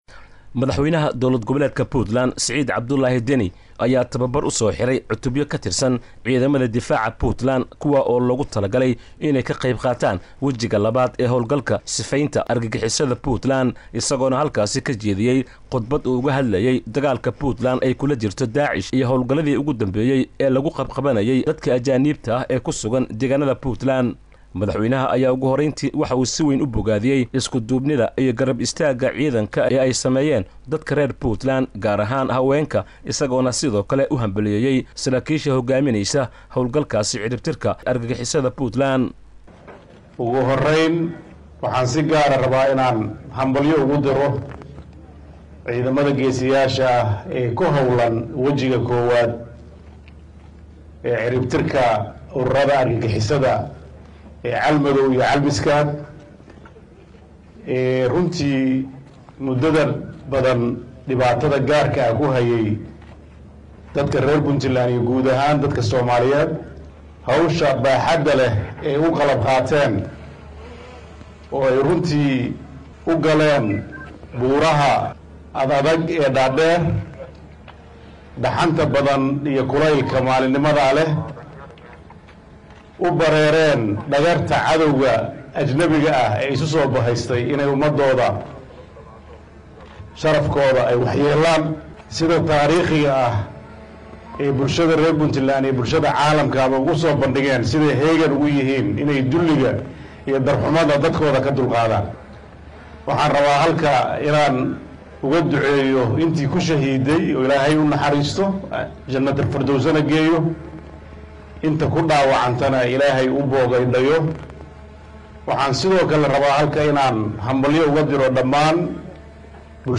Madaxweynaha Puntland Siciid Cabdullahi Deni oo qudbo dheer ka jeediyay dagaalka lagula jiro Daacish iyo arimo kale.